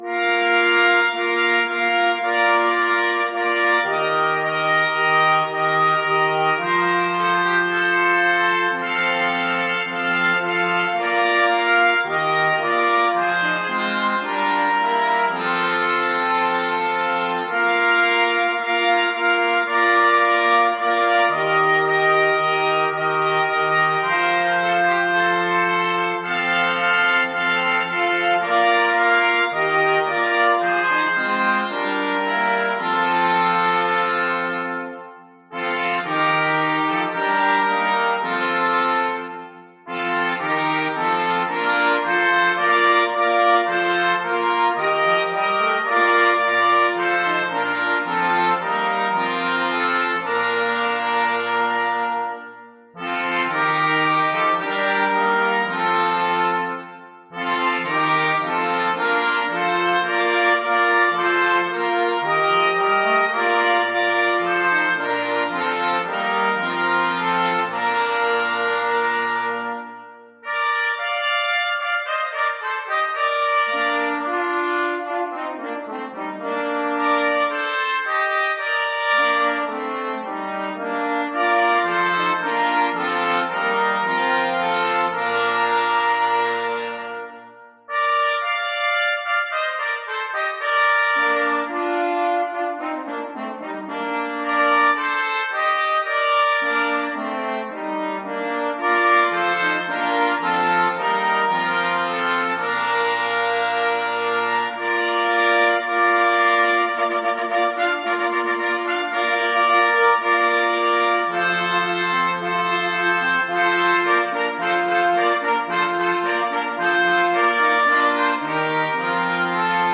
Free Sheet music for Brass Quartet
Trumpet 1Trumpet 2French HornTrombone
Bb major (Sounding Pitch) (View more Bb major Music for Brass Quartet )
4/4 (View more 4/4 Music)
Classical (View more Classical Brass Quartet Music)